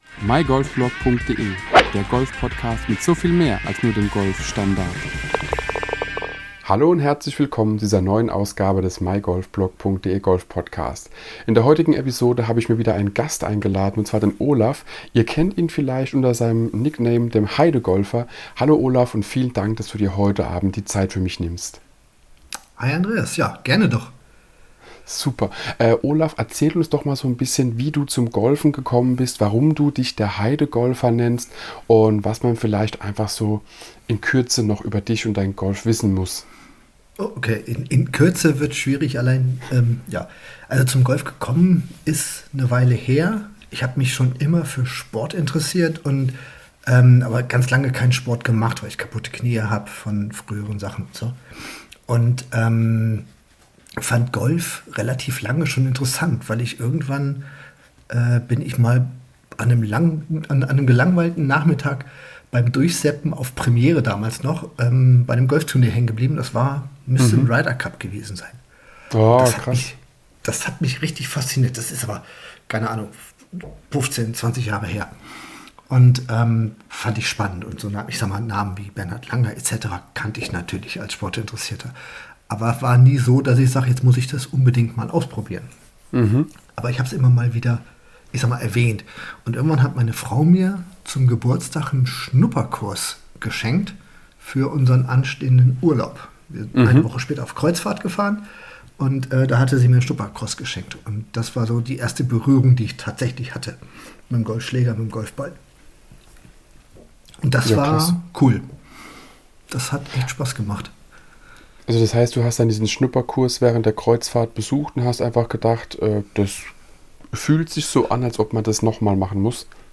Stell dir vor, zwei Golfer treffen sich im Clubhaus nach der Runde und fangen einfach an zu erzählen. Die Themen wechseln, man tauscht sich aus und gewährt Einblicke in diverse Aspekte.